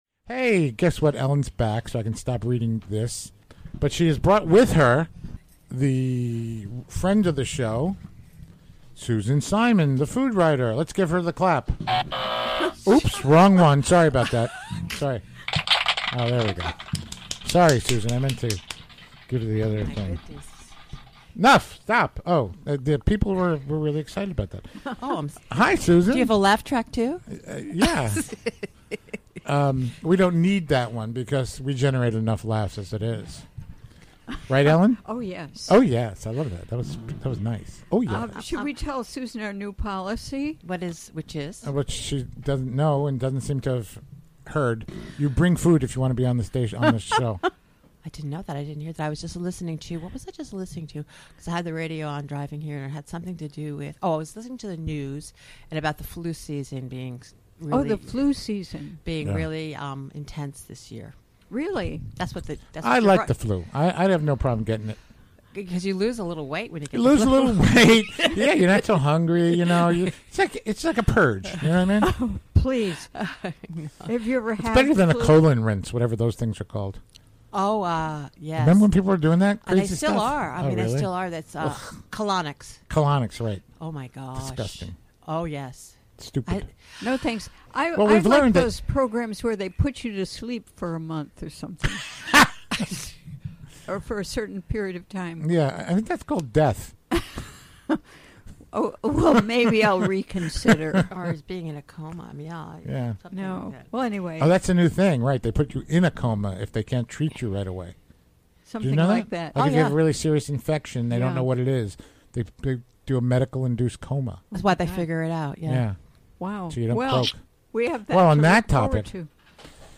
Recorded live during the WGXC Afternoon Show, Thu., Dec. 14.